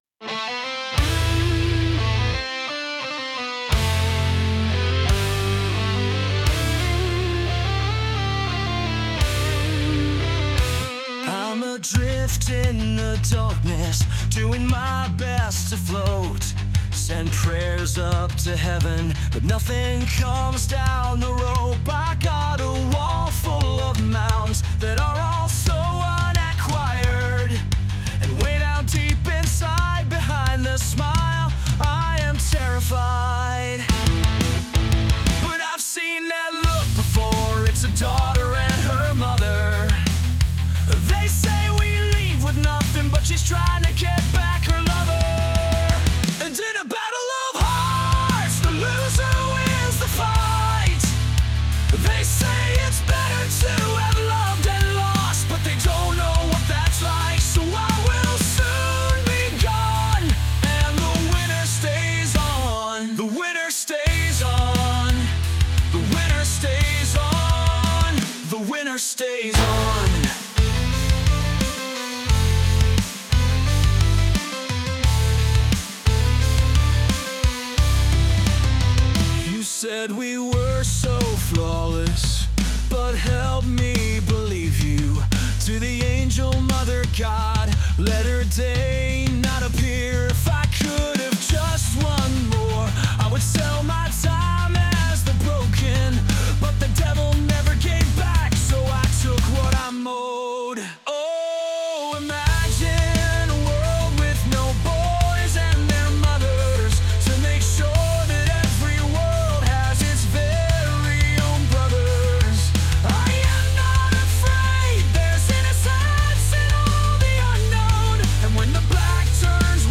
• Winner Stays On（ロック）： ロックというジャンルとタイトルを指定して生成しました。初期の出力はカントリー調でしたが、「Epic Rock」というジャンルに変更することで、より力強いロックサウンドに仕上がりました。